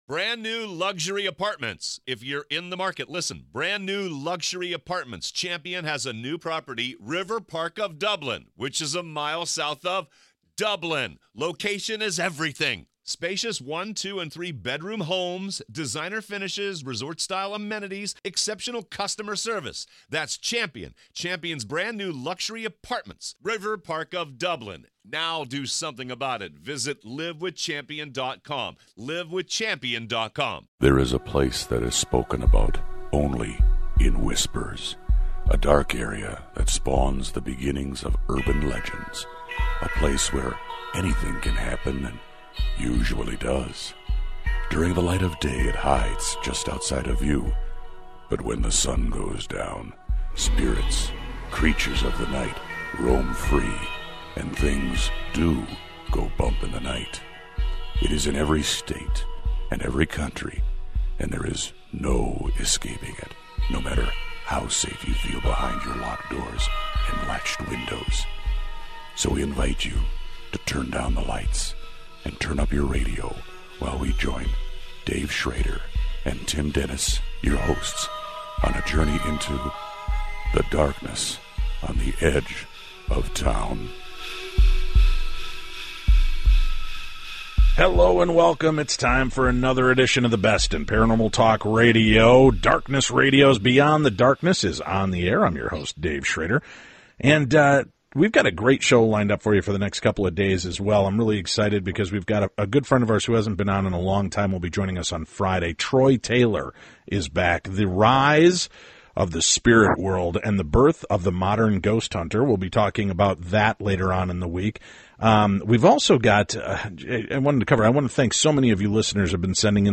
square off in a lively debate and discussion about the possibilities of the paranormal and how Philosophy deals with these concepts.